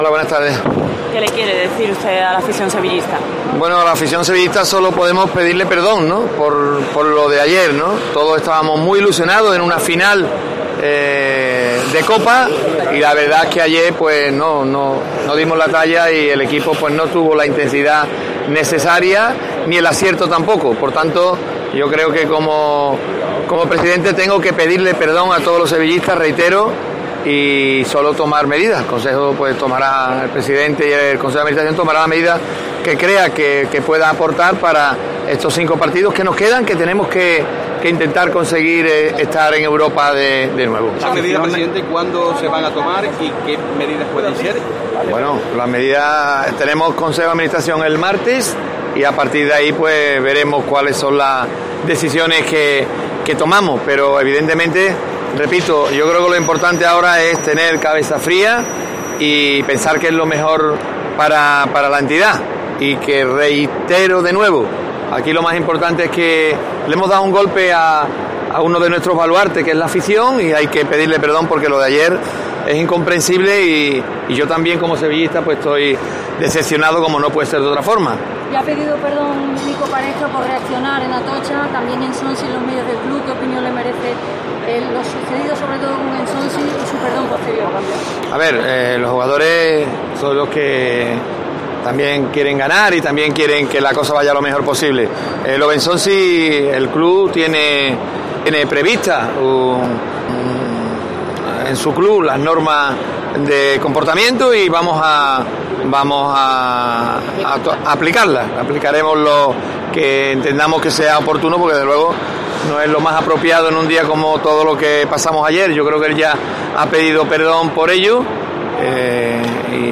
El máximo dirigente del Sevilla atendió a los periodistas tras arribar el tren que les trajo de Madrid y señaló que sólo puede "pedirle perdón a la afición por lo de ayer ".